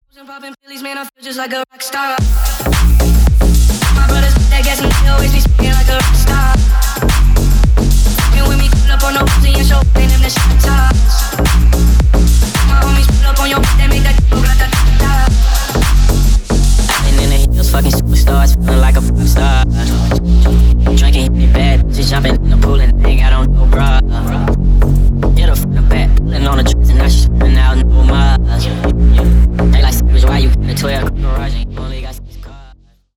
Ремикс # Поп Музыка
ритмичные